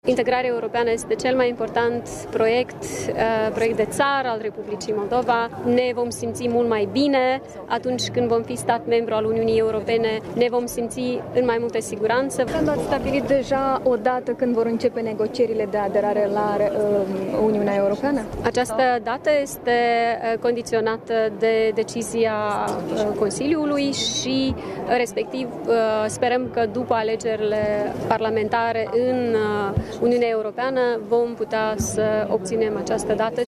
Președinta Maia Sandu a prezentat câteva explicații pe această temă, la Chișinău, la evenimentele dedicate Zilei Europei.